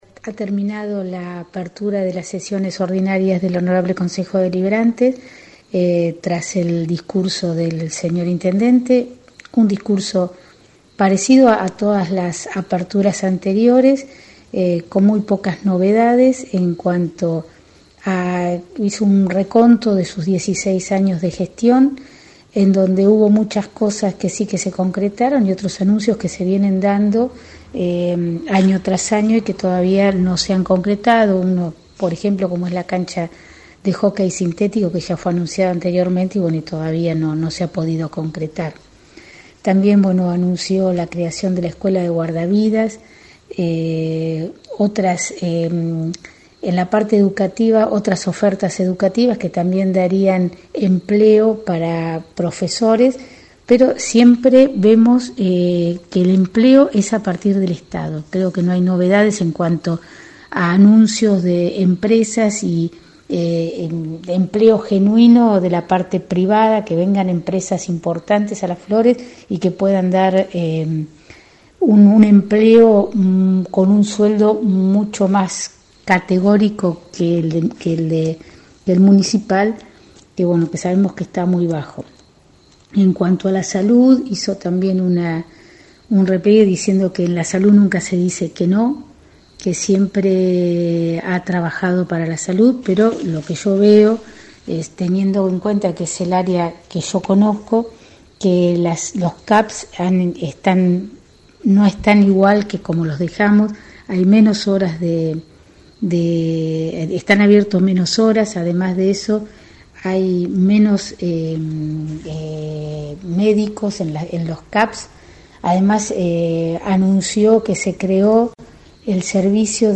Las voces de los concejales de distintos bloques del Concejo Deliberante:
Concejal por unibloque Pro Alejandra Quintieri: “No hay anuncios de empresas privadas que vengan y ofrezcan trabajo genuino..”
quintieri-reflexion-discurso-de-gelene.mp3